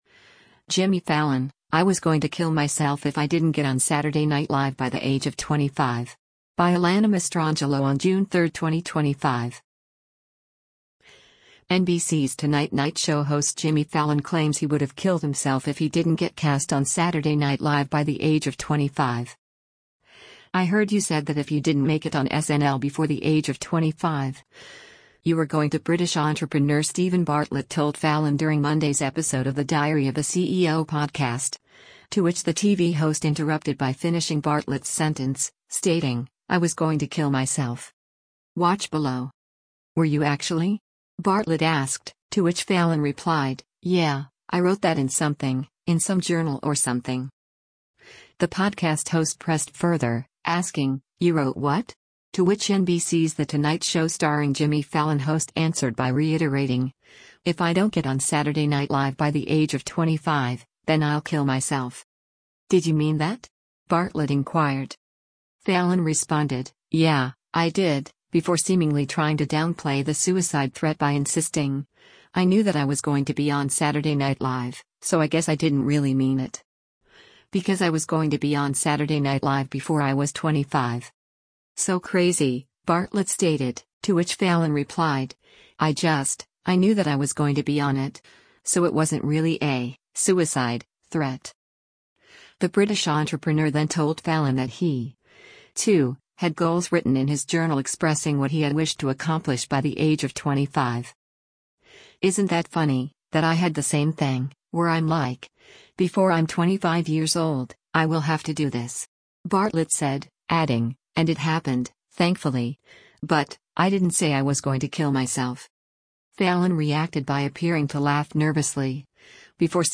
Fallon reacted by appearing to laugh nervously, before seemingly trying to downplay his suicide threat yet again — this time, by switching the narrative from his earlier assertion of “Yeah, I did” write that down to “I think” the remark was drafted.